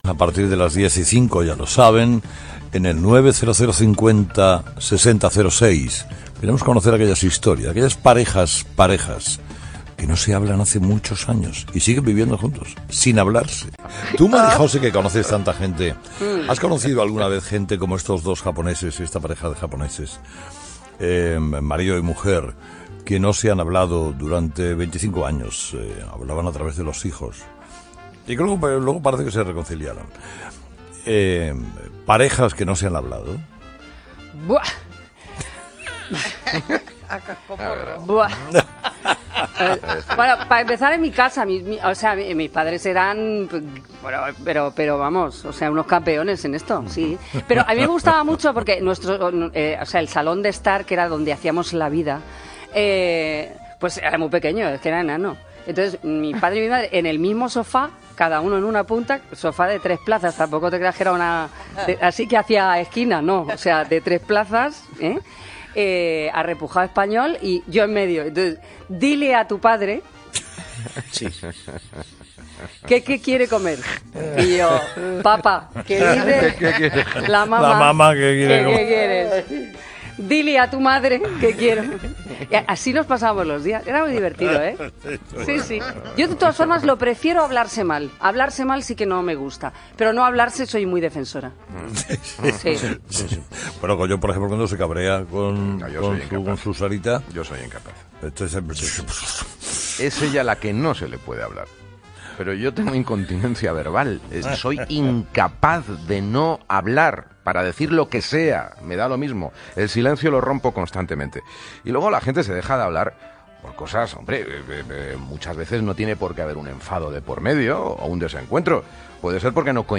Espai "La hora de los fósforos" sobre el tema de les parelles que no es parlen. Presentació del tema, opinions dels col·laboradors i trucada d'una oïdora.
Entreteniment
FM